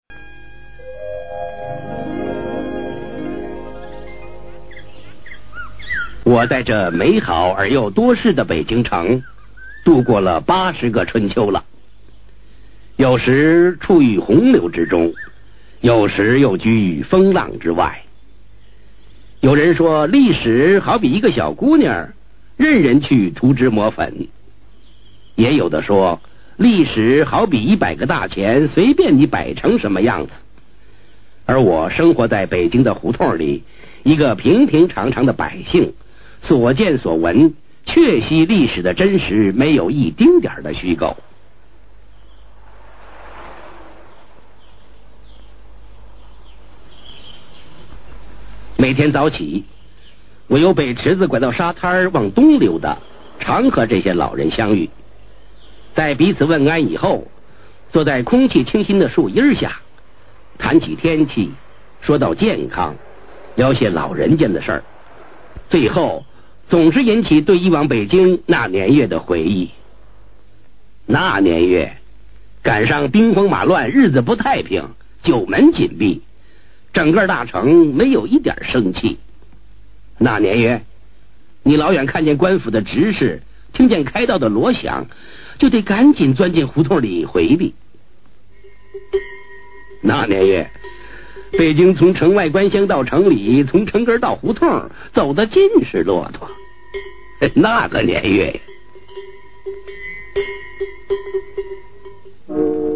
[4/6/2009]【绝版】老北京的叙说（新影1984年拍摄的珍贵纪录片）